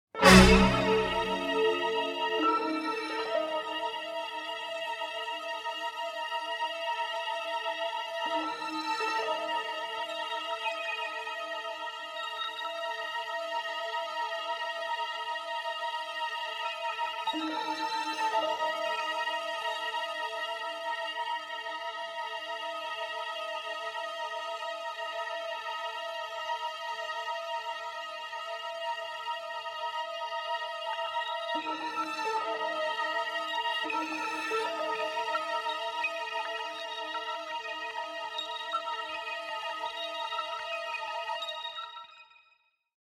original motion picture score
restored, remixed and remastered in stereo